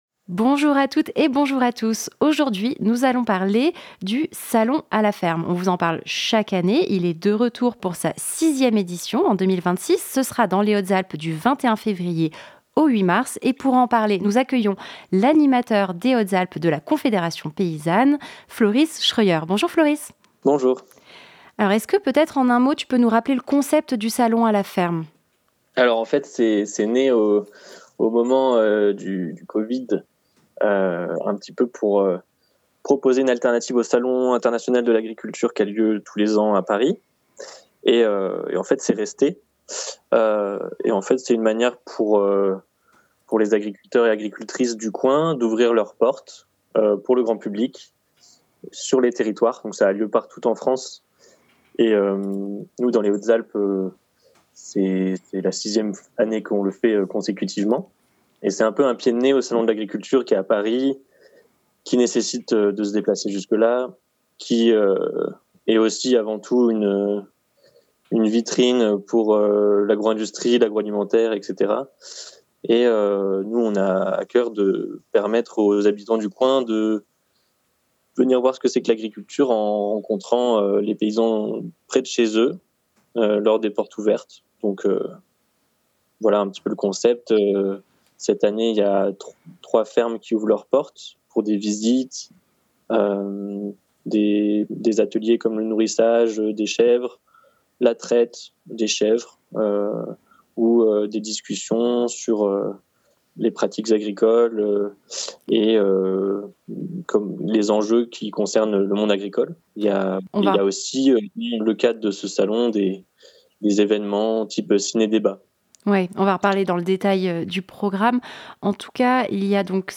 En parallèle du Salon international de l'agriculture à Paris, la Confédération Paysanne propose le salon à la ferme pour permettre aux habitant.e.s des Hautes-Alpes de rencontrer les agriculteurs et de parler alimentation. Entretien